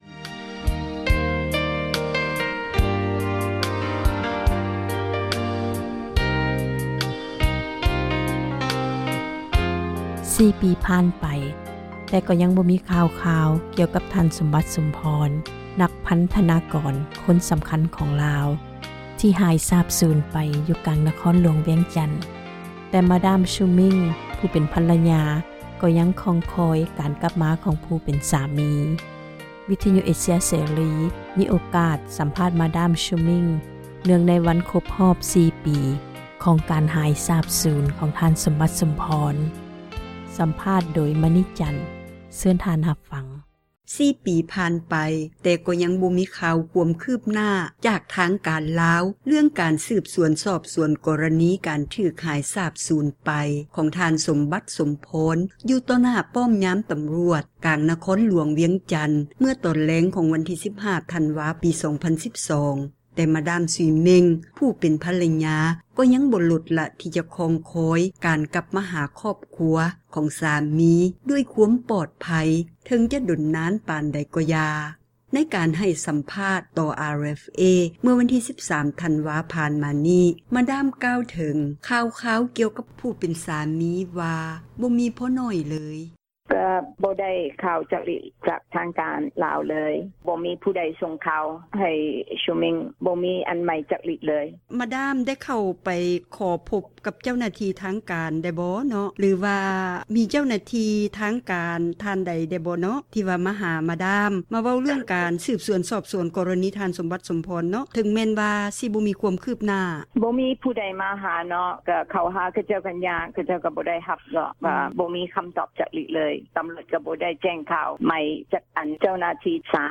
ວິທຍຸ ເອເຊັຽ ເສຣີ ມີໂອກາດ ສັມພາດ